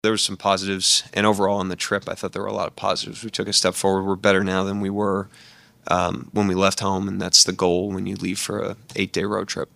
Thunder head coach Mark Daignault.